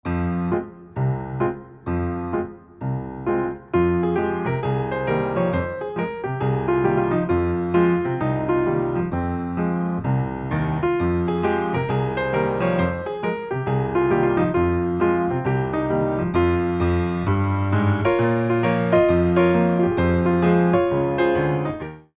29 Original Piano Pieces for Ballet Class
Centre Tendus / Pirouettes
mod. 4/4 - 1:05